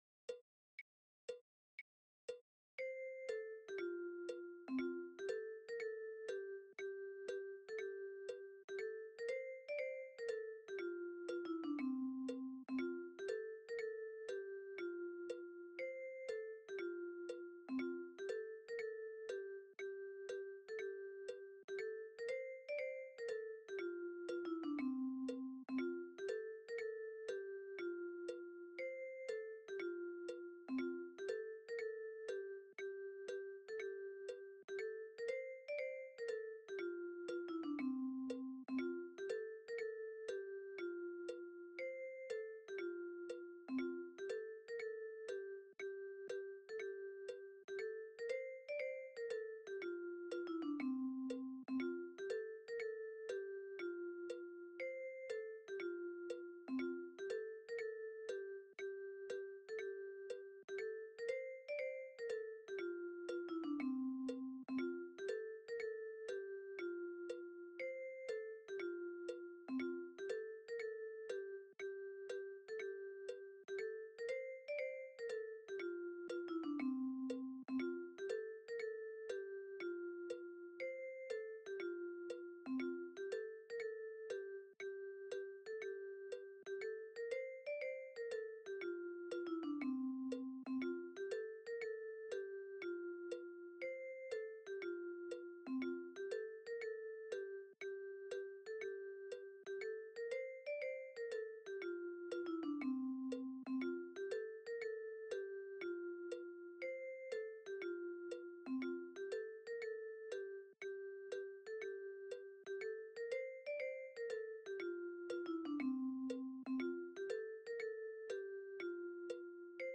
Rondes enfantines illustrées par Monique Gorde
Musique : Trois jeunes tambours - piano - 2,58 Mo - 3 mn 31